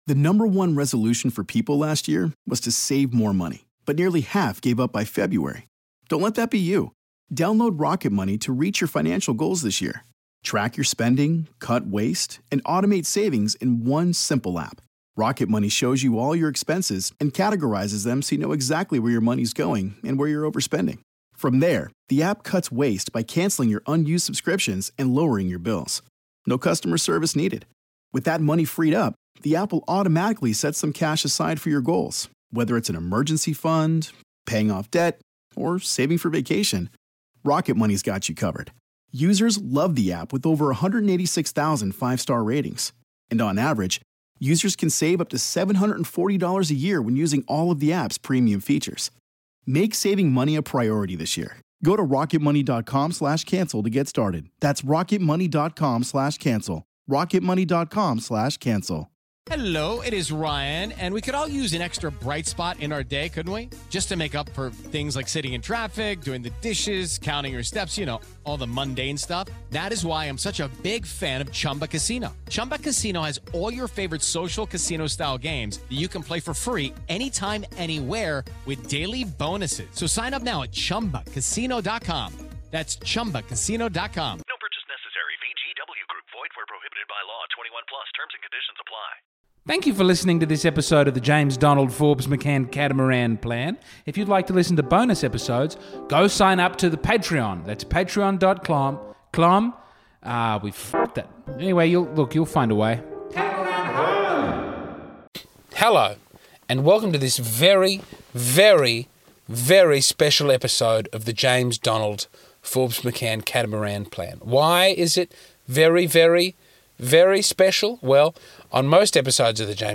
Sorry for the minimal editing this week, I know you all love the editing as much as I love doing the editing.